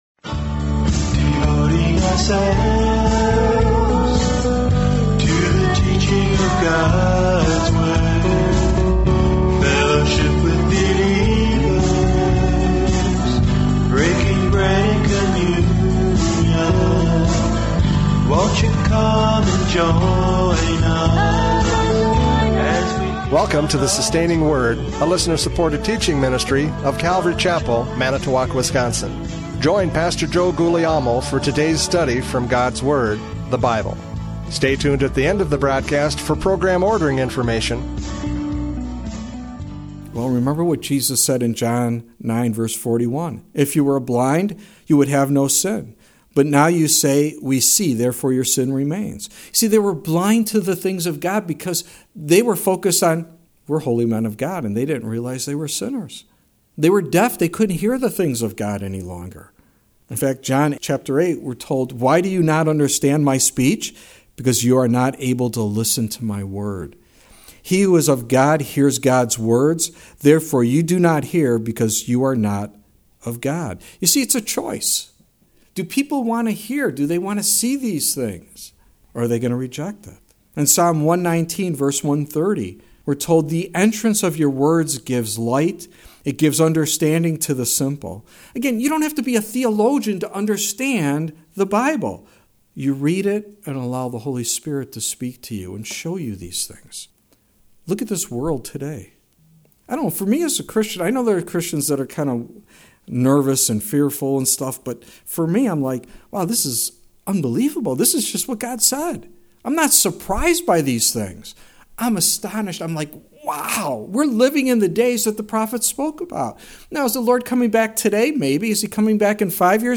John 10:1-10 Service Type: Radio Programs « John 10:1-10 The Good Shepherd!